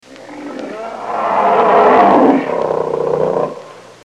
BearRoaring.wav